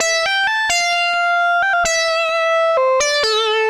Index of /musicradar/80s-heat-samples/130bpm